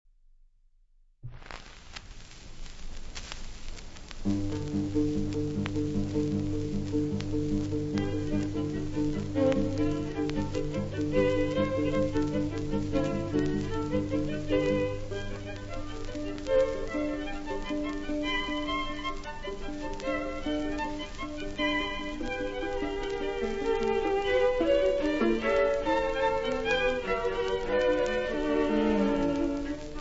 Viola
Violino
Violoncello
• Registrazione sonora musicale